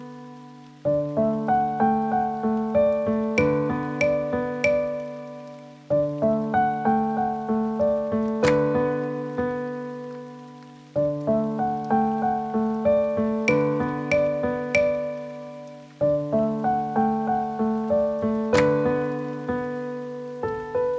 Produkt zawiera utwór w wersji instrumentalnej oraz tekst.